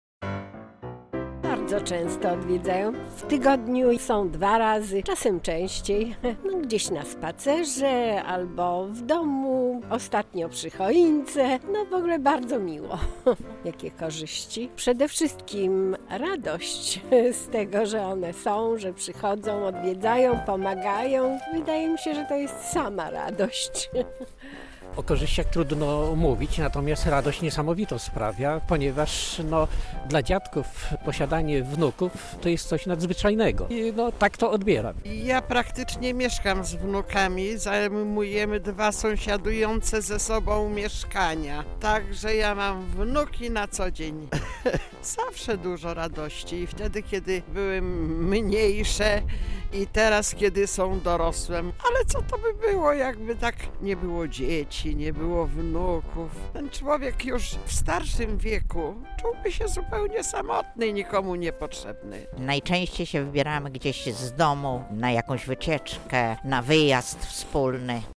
Jak zaś mieszkańcy Lublina dbają o swoich seniorów? O to zapytaliśmy dziadków i babcie.